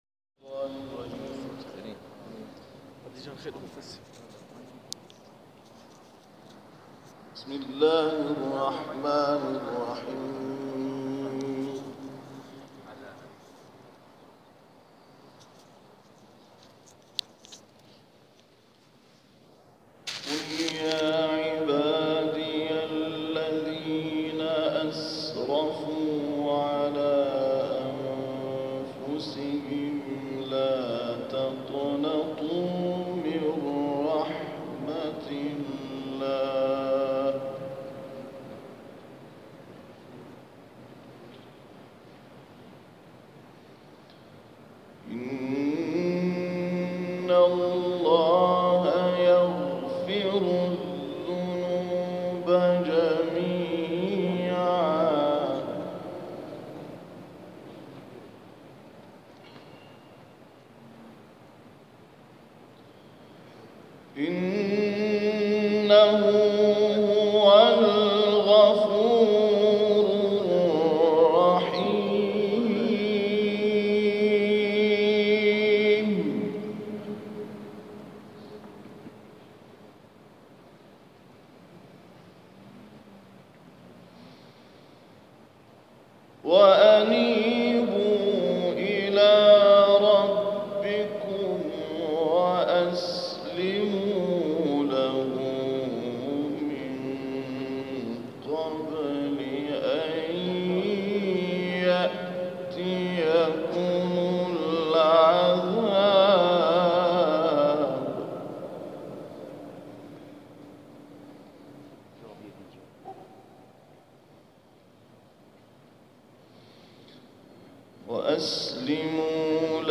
محفل قرآنی در جوار حرم بنیانگذار انقلاب اسلامی + عکس و تلاوت